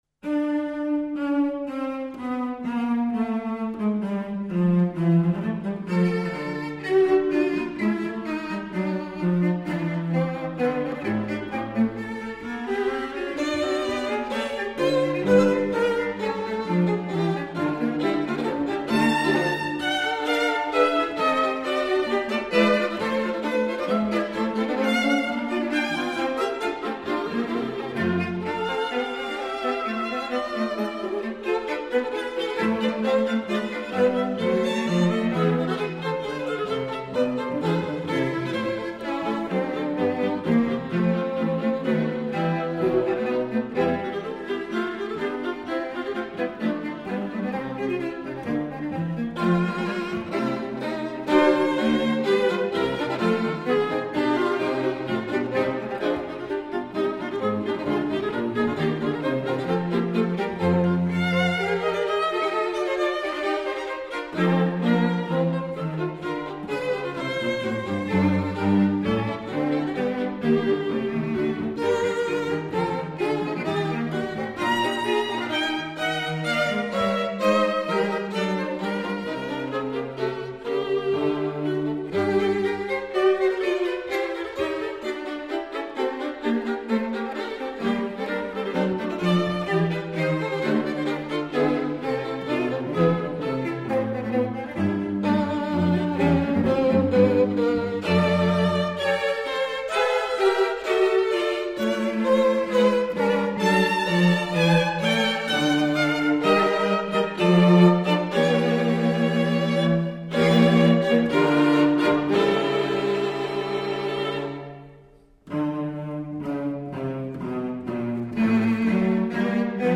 Allegro